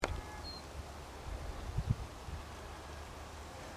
Čuņčiņš Phylloscopus collybita sub-tristis, Phylloscopus collybita sub-tristis
Ierakstā dzirdamie bija diezgan klusi (ieskaņoti no ~10 m attāluma), intervāls starp saucieniem tāds, kā pirmajā ierakstā, citu variāciju saucienam nebija.